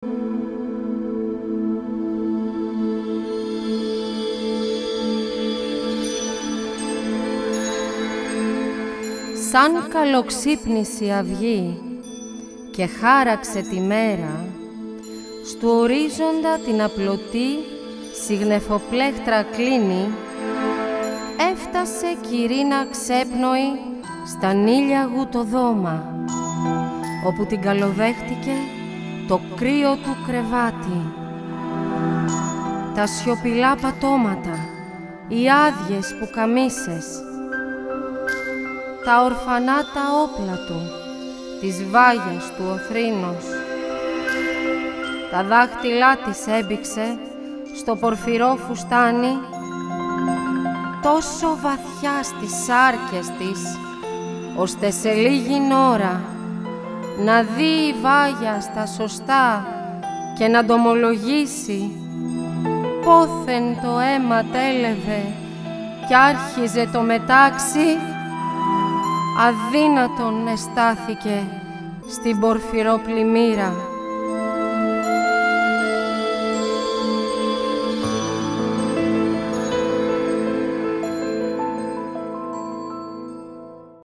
Το μουσικό έργο Μελανοχτυπημένη αποτελείται απο 7 θέματα τα οποία δημιουργήθηκαν ως μουσική υπόκρουση για την απαγγελία των έμμετρων ποιημάτων του ομότιτλου μυθιστορήματος της Αρχοντούλας Αλεξανδροπούλου.
ΑΠΑΓΓΕΛΙΕΣ